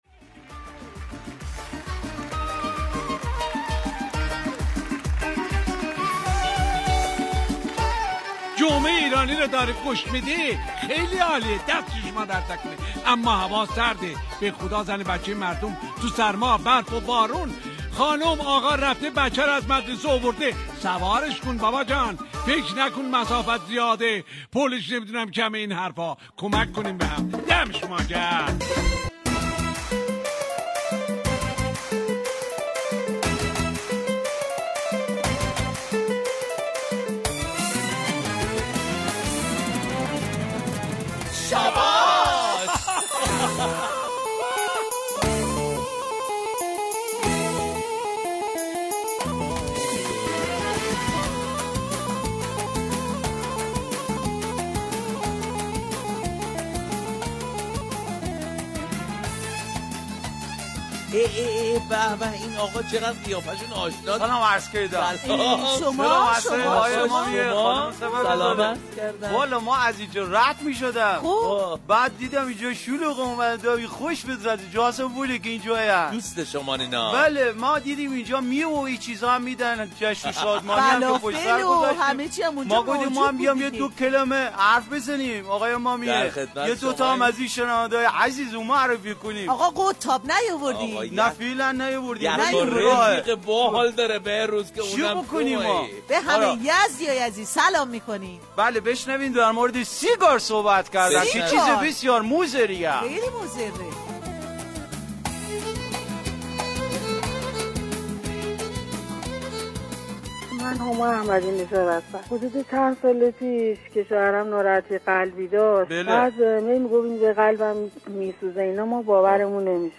موزیک های شاد
برنامه طنز رادیو ایران